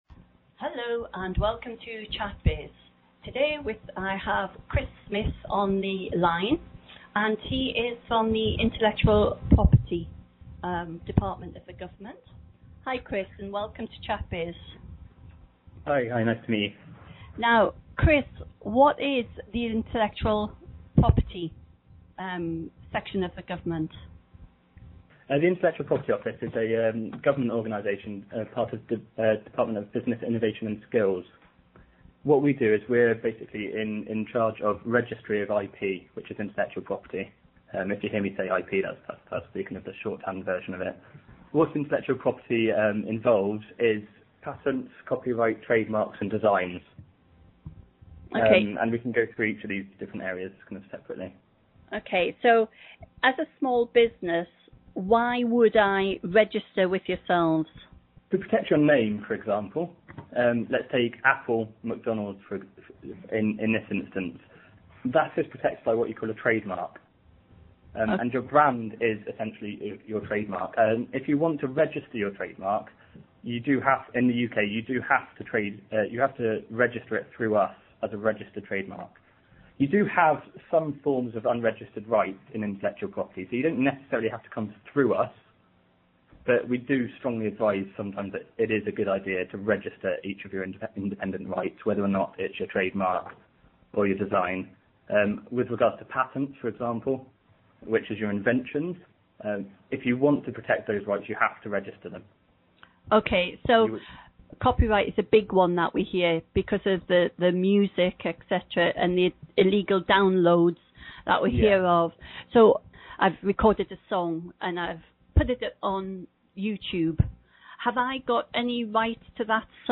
We apologise for the sound quality of the telephone line.